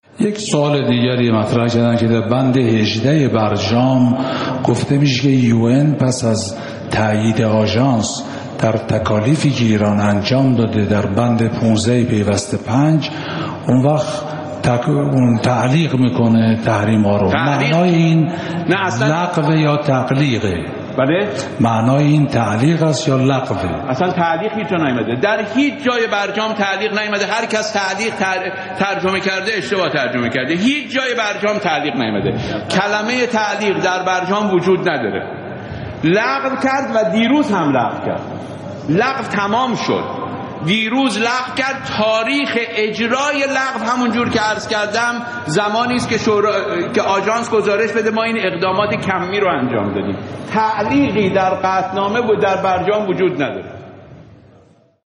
همان ایام بود که ظریف در مقابل نمایندگان ملت  حاضر شد و گفت: در برجام اصلاً تعلیق نداریم !
Zarif-majles-laghve-tahrim.mp3